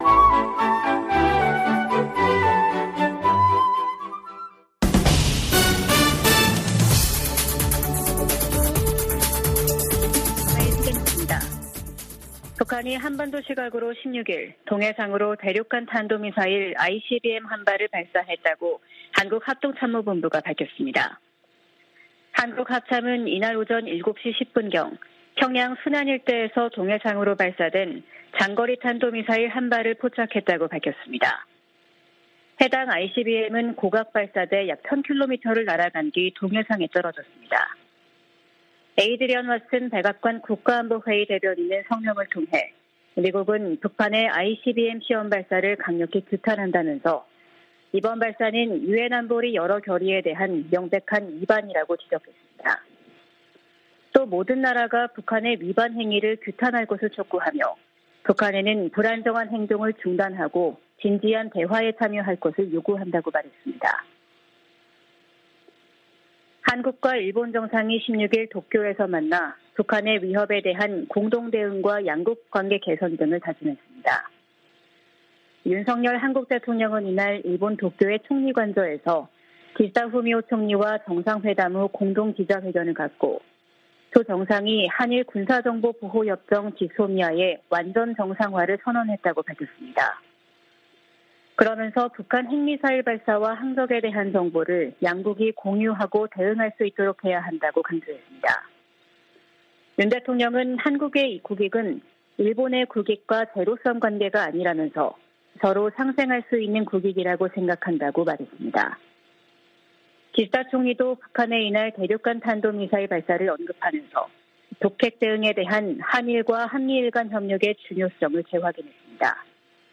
VOA 한국어 아침 뉴스 프로그램 '워싱턴 뉴스 광장' 2023년 3월 17일 방송입니다. 윤석열 한국 대통령과 기시다 후미오 일본 총리가 정상회담을 통해 북핵과 미사일 위협에 대응한 공조를 강화하기로 했습니다. 북한이 16일 ‘화성-17형’으로 추정되는 대륙간탄도미사일(ICBM)을 발사했습니다. 미국 정부는 북한의 ICBM 발사가 안보리 결의에 위배되고 역내 긴장을 고조시킨다며 강력 규탄했습니다.